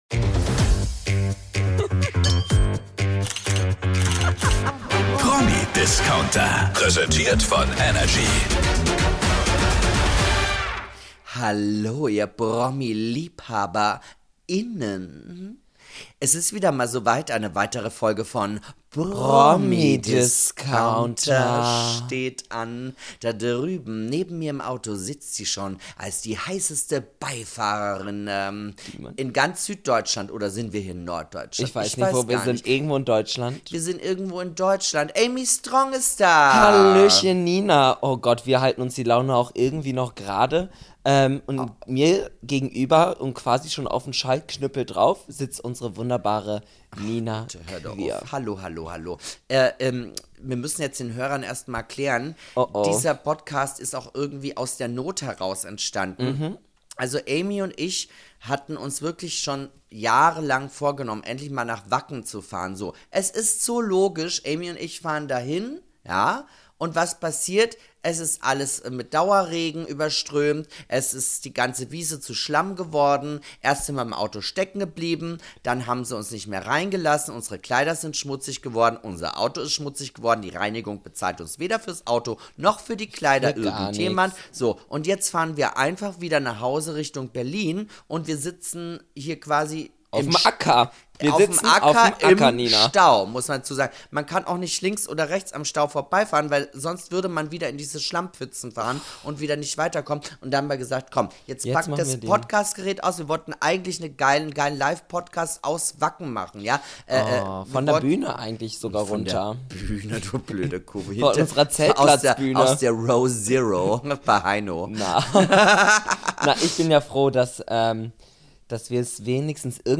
Quasi Live von der Landstraße, weg vom im Schlamm versunkenen Wacken Festival, geht es wieder um die neusten Geschichten der Schöneren und Besseren. Da haben wir ein absichtlich ausgelöstes Erdbeben. Die schlimmsten Sachen, die Stars auf die Bühne geschmissen wurden und eine Vaterschaft, die Frauen auf der ganzen Welt in Trauer stürzt.